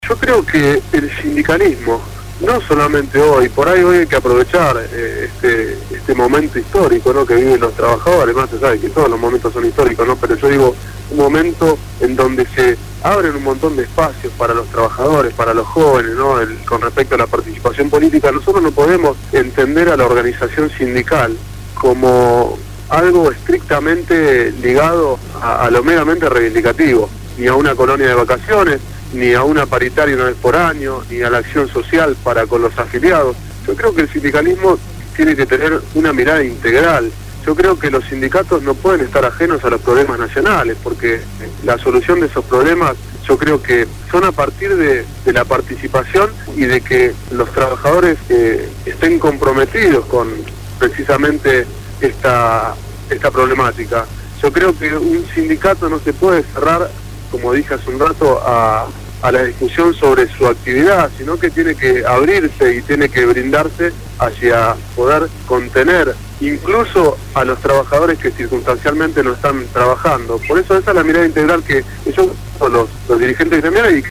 Facundo Moyano, Secretario General del Sindicato Unico de Trabajadores de Peajes y Afines (SUTPA) fue entrevistado en «Voces Portuarias» (Martes